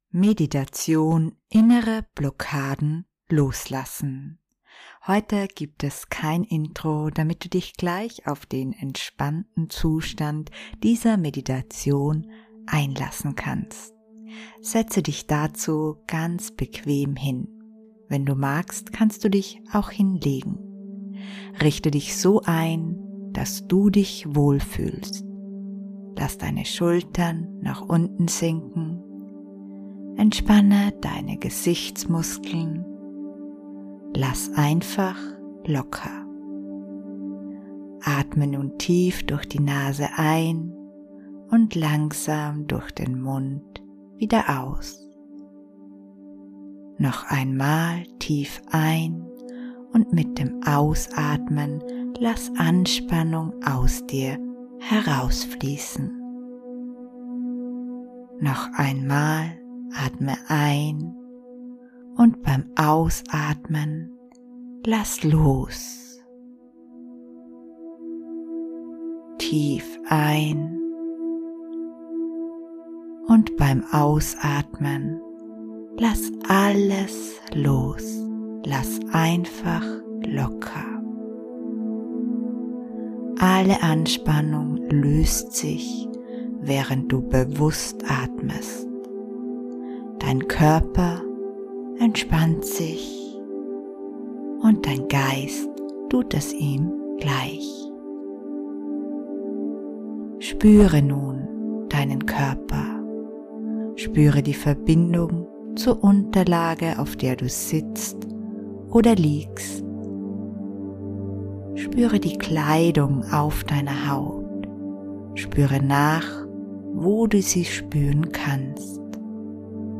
In dieser Meditation kannst du innere Blockaden lösen oder Dinge, die du nicht mehr tragen möchtest loslassen. Eine integrierte Phantasiereise und tiefenwirksame Affirmationen helfen dabei.